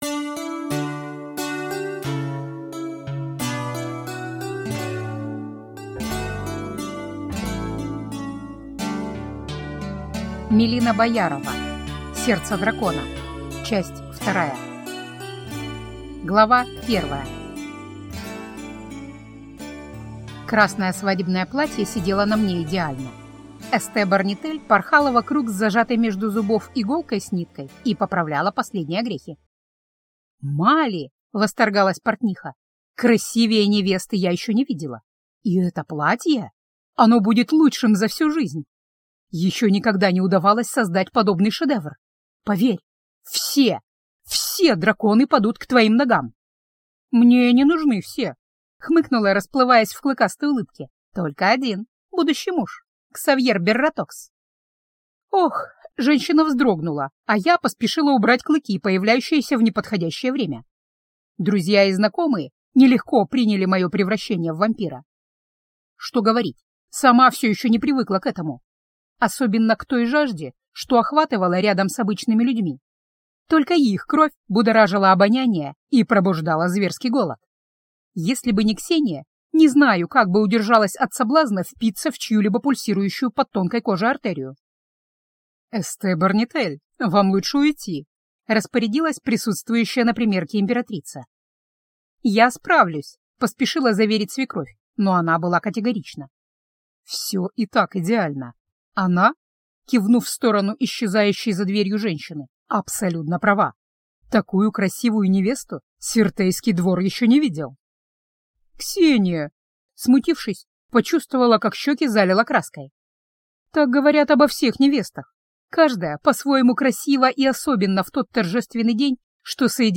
Аудиокнига Сердце дракона. Часть 2 | Библиотека аудиокниг